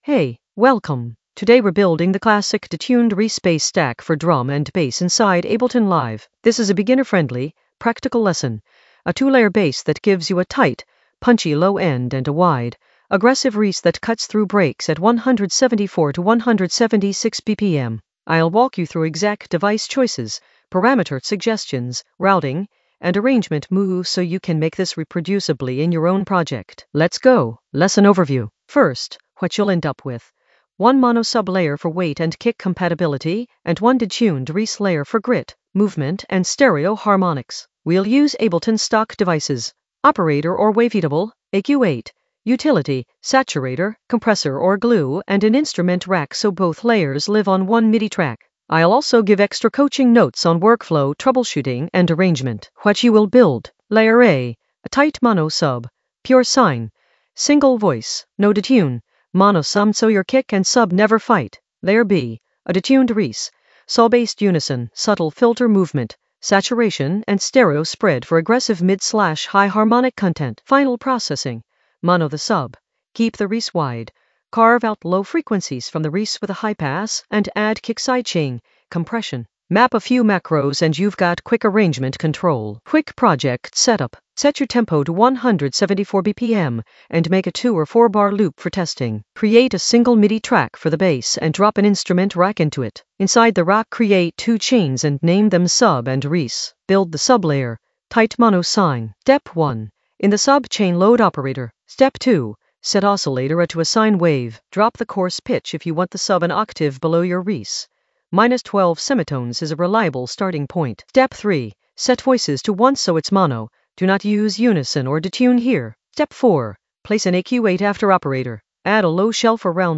An AI-generated beginner Ableton lesson focused on Intro to detuned reese layers in the Basslines area of drum and bass production.
Narrated lesson audio
The voice track includes the tutorial plus extra teacher commentary.